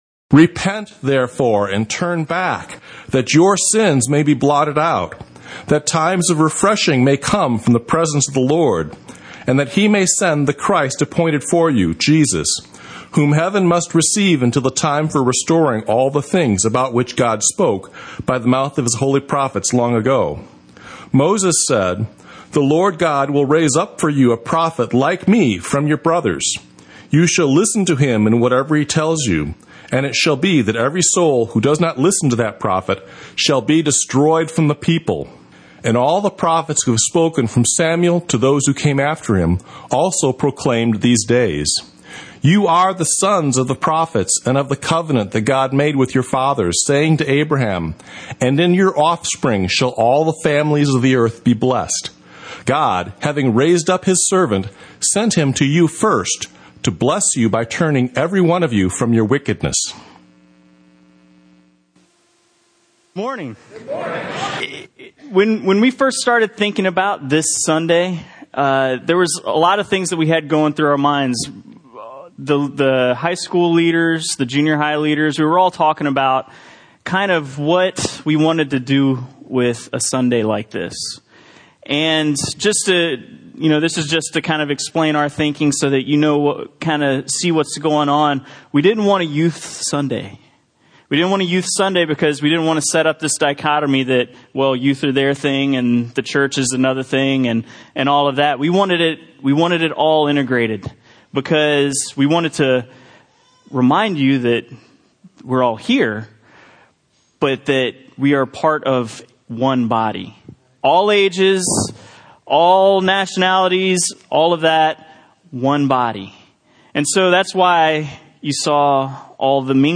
Acts Series message 7 Text: Acts 3:19-26 The people have just witnessed the lame leap like deer leading Peter to direct them to the only one with the authority and power to heal physically and spiritually, Jesus.